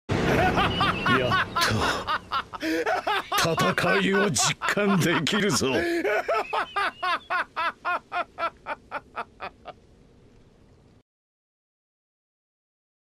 madara laugh sound effects
madara-laugh